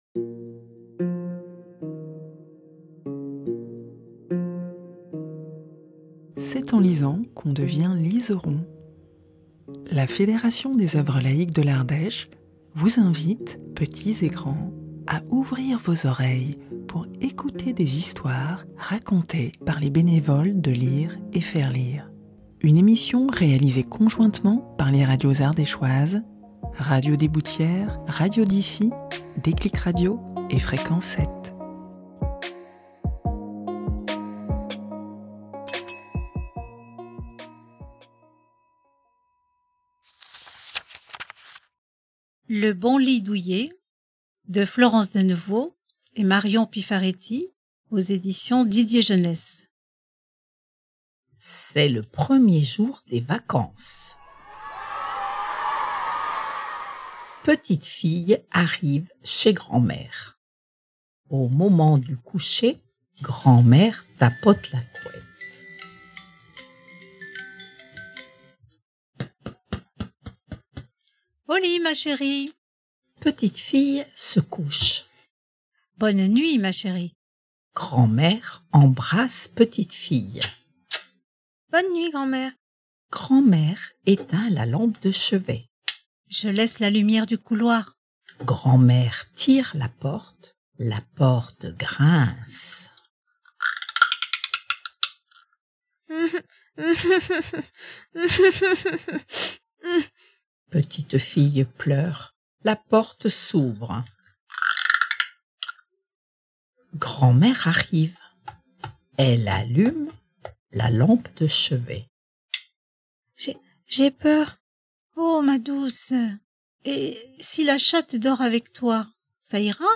Une chronique initiée par la FOL (Fédération des œuvres laïques) de l'Ardèche, contée par les lecteurs de l'association Lire et Faire Lire et réalisée conjointement par les radios associatives d'Ardèche (RDB, Déclic radio, Radio d'ici et Fréquence 7). Au programme : contes, poésie, histoires courtes...